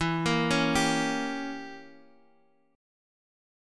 Gdim/E chord
G-Diminished-E-x,x,2,3,2,3-8.m4a